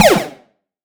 SniperGuySFX.wav